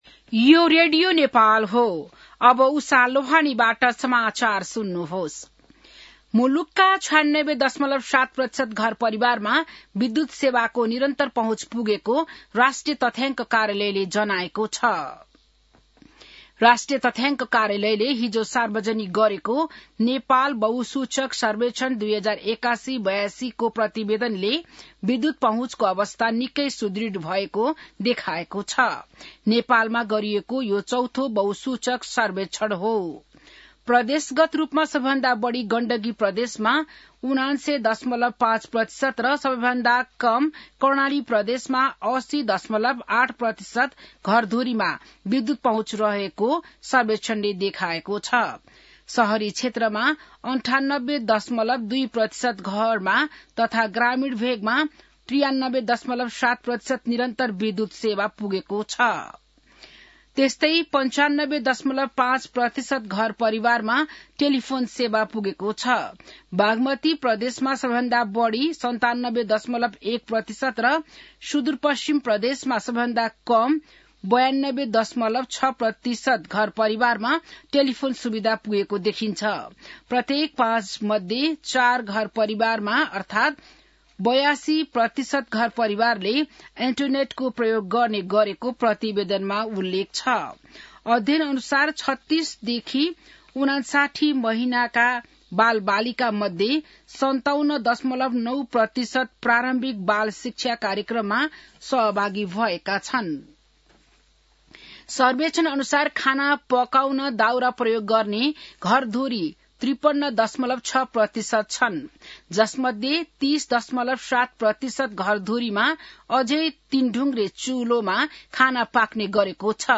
बिहान १० बजेको नेपाली समाचार : २७ माघ , २०८२